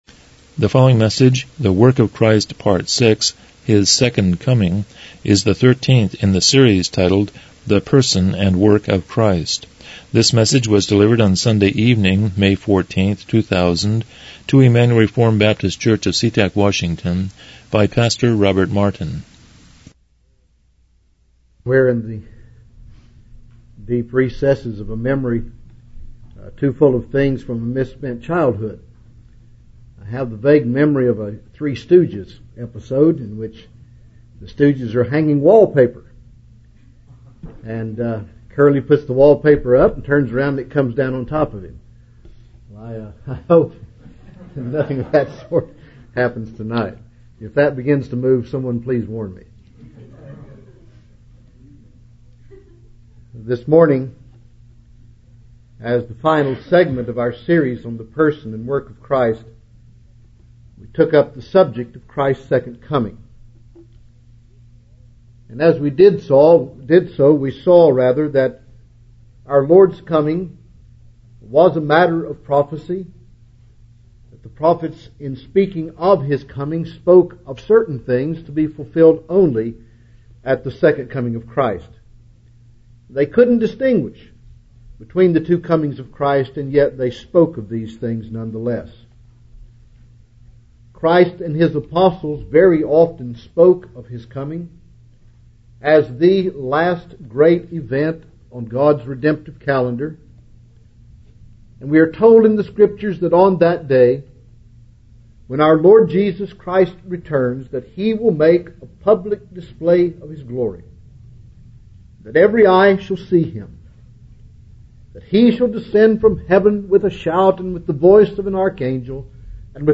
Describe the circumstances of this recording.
Series: The Person and Work of Christ Service Type: Evening Worship « 12 The Work of Christ #5